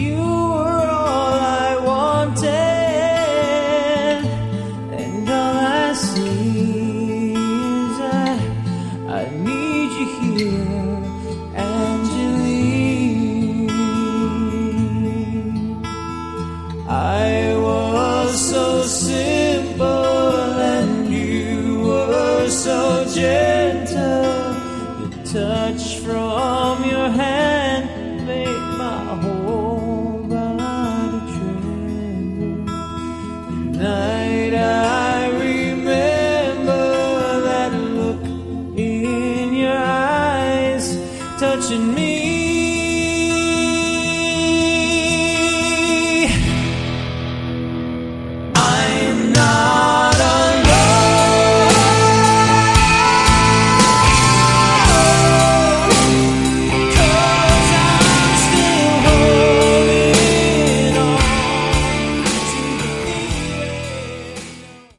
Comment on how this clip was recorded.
Originally recorded in 1988